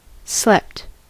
Ääntäminen
Ääntäminen US Tuntematon aksentti: IPA : /slɛpt/ Haettu sana löytyi näillä lähdekielillä: englanti Slept on sanan sleep partisiipin perfekti. Slept on sanan sleep imperfekti.